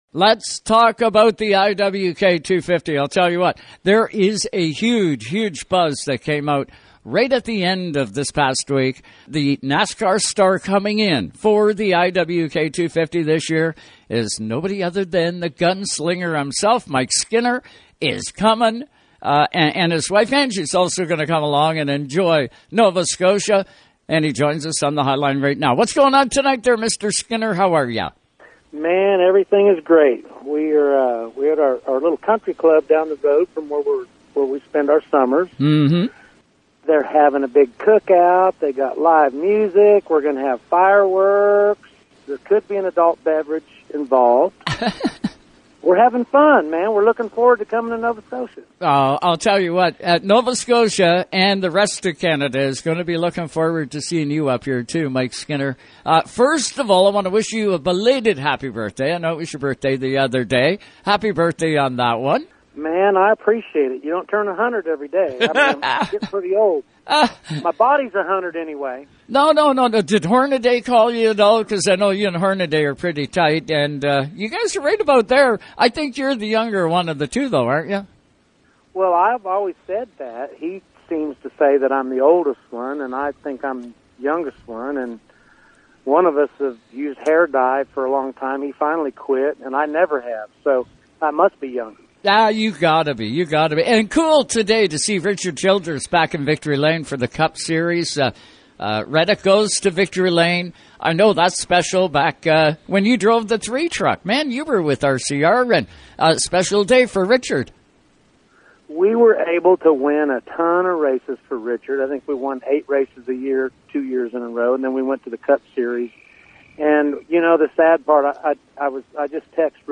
Mike Skinner, this year’s NASCAR celebrity driver of the Cat Car for Kids in the IWK 250 on Saturday, July 23, was a guest on Race Time Radio Sunday night.